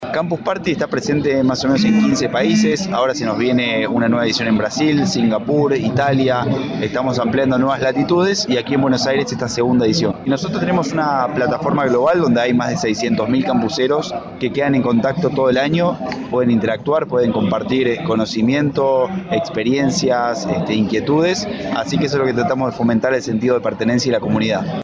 Ciclo 2018, Entrevistas
SobreCiencia está presente en Campus Party 2018 que se realiza hasta el 28 de abril en Tecnópolis, Argentina